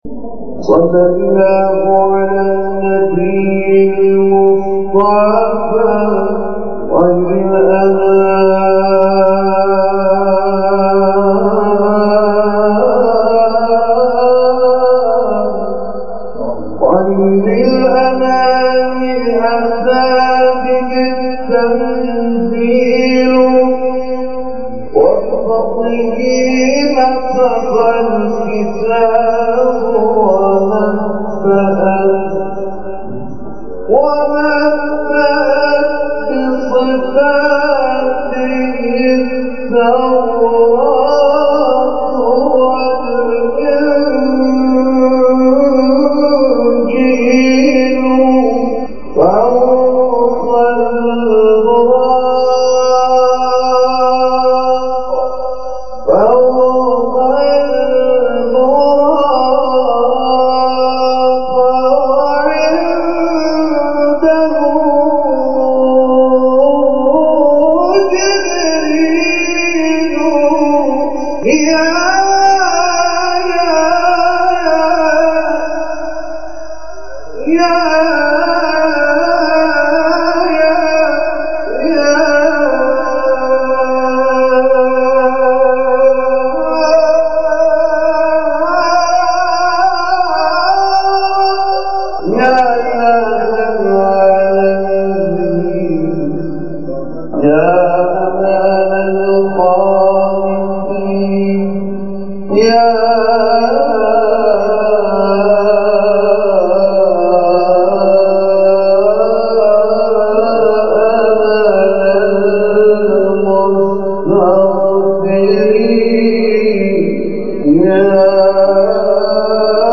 از طرفی ایشان بسیار شوخ و خوش‌صدا هم بود و جنس صدایشان همان جنس صدای مداح‌های آذری‌زبان بود.
صدای گرمی هم داشت و همین موجب شده بود تا در زمینه ابتهال بتواند فعالیت بیشتری داشته باشد.
ابتهال ، تلاوت قرآن